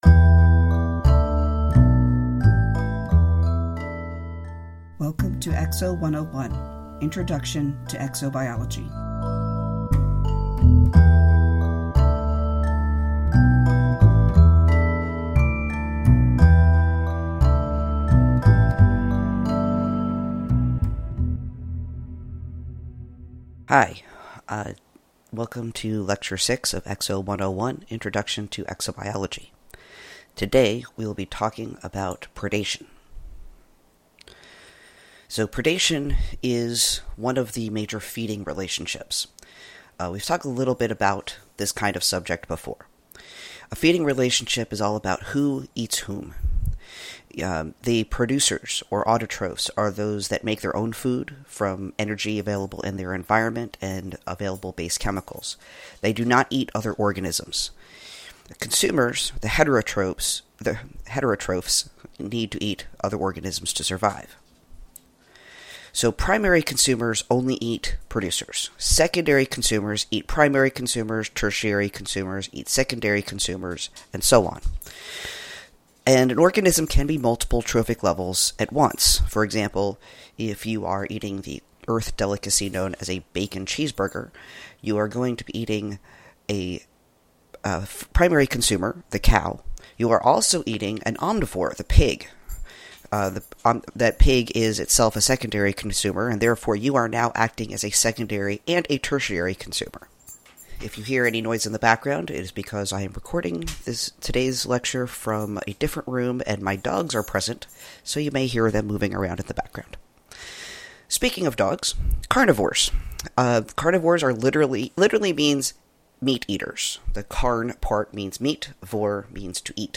In today’s lecture, we discuss predation. https